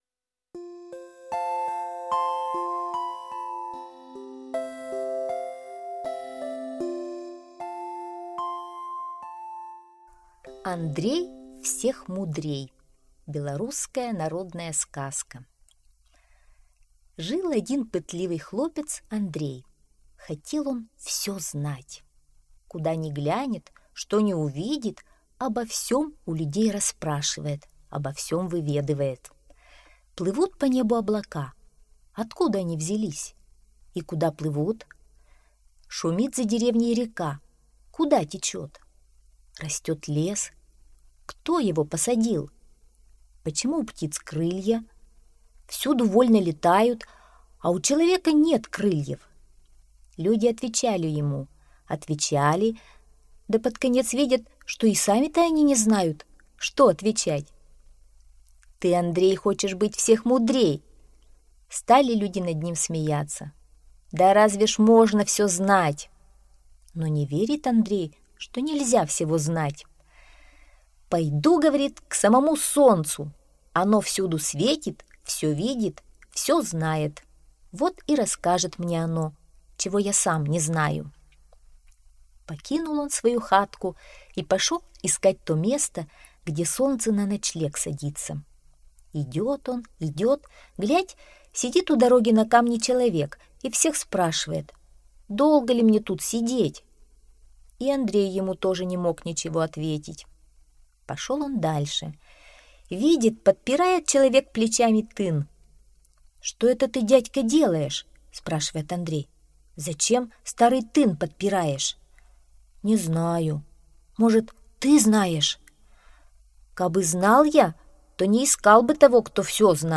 Андрей всех мудрей - Версия 2 - белорусская аудиосказка